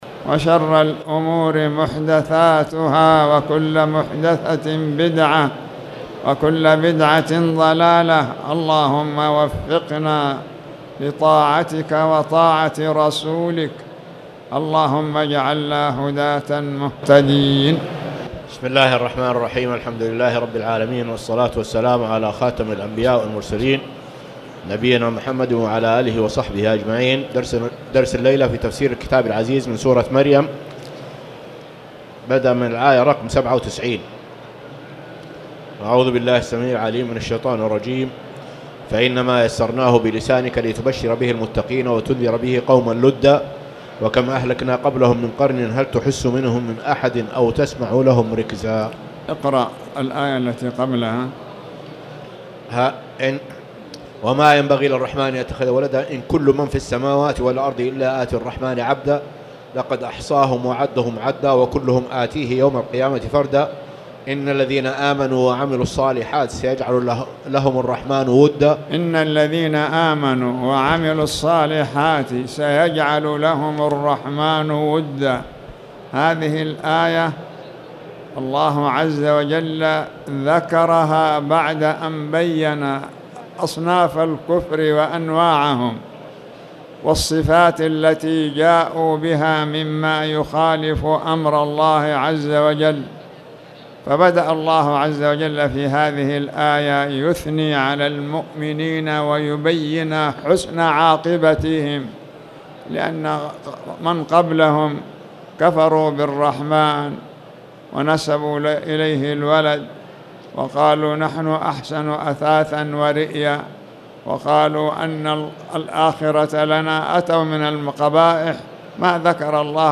تاريخ النشر ١٦ جمادى الأولى ١٤٣٨ هـ المكان: المسجد الحرام الشيخ